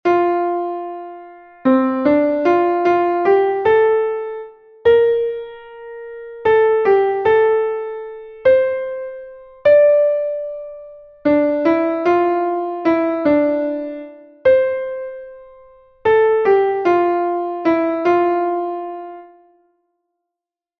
Here there are four six eight time signature exercises.